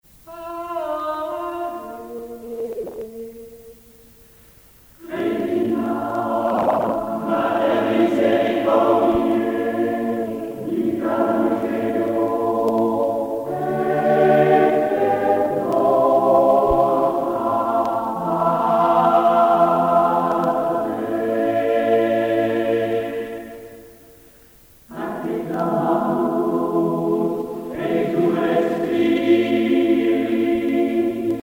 dévotion, religion
Pièce musicale éditée